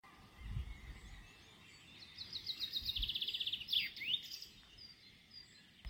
Paprastasis kikilis
(Fringilla coelebs)
Paukščiai